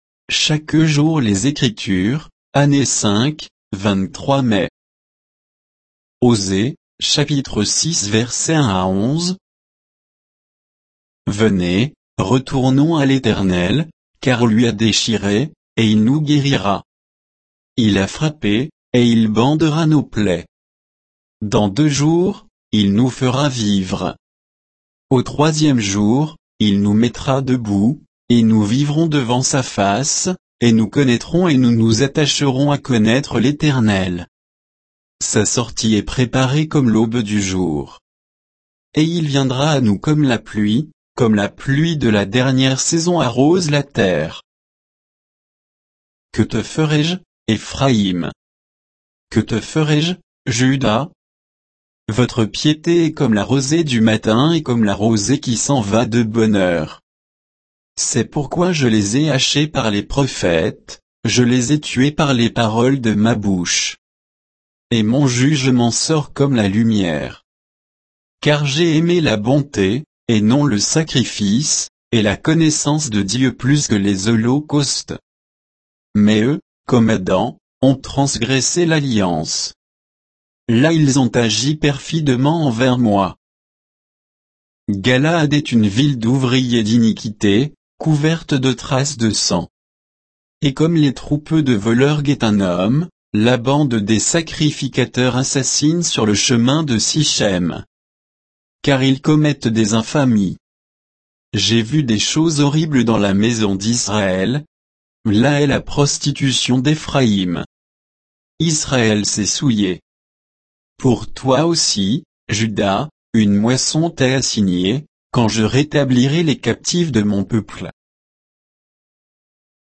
Méditation quoditienne de Chaque jour les Écritures sur Osée 6